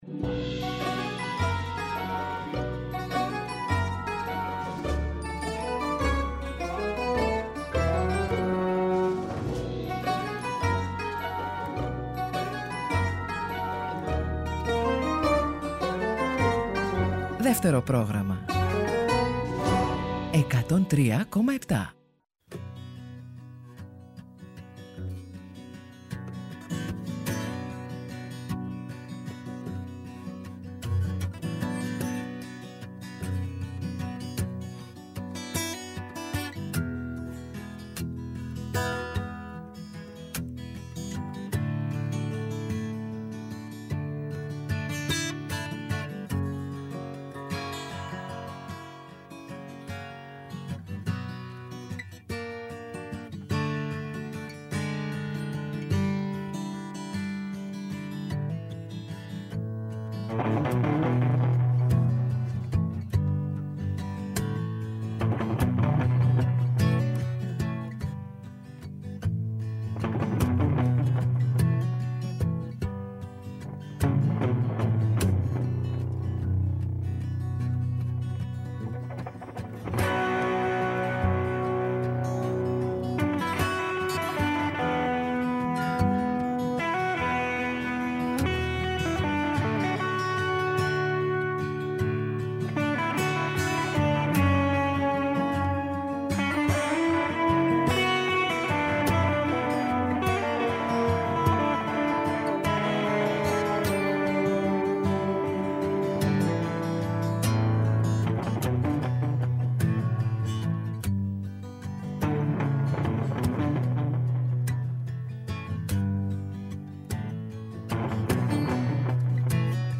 “Ροκ συναναστροφές” με το …”ροκ” όχι μόνο ως μουσική φόρμα, αλλά περισσότερο ως στάση ζωής. Αγαπημένοι δημιουργοί και ερμηνευτές αλλά και νέες προτάσεις, αφιερώματα και συνεντεύξεις, ο κινηματογράφος, οι μουσικές και τα τραγούδια του.